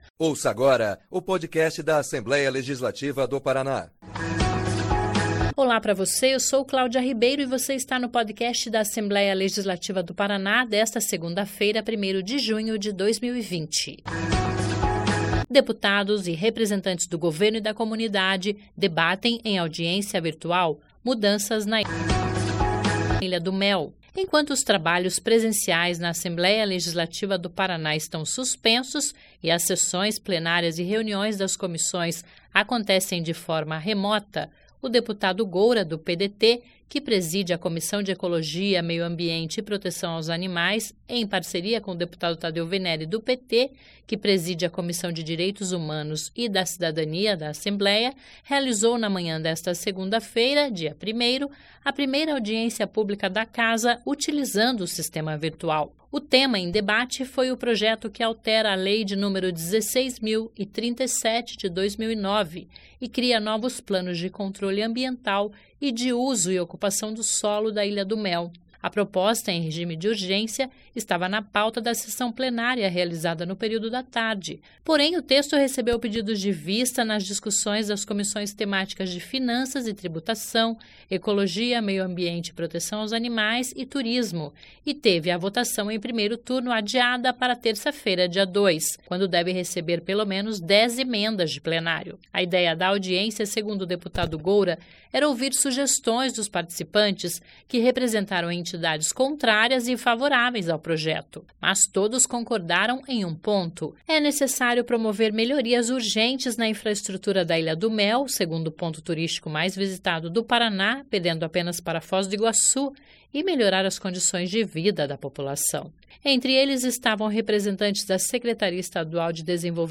Deputados e representantes do Governo e da comunidade debatem, em audiência virtual, mudanças na Ilha do Mel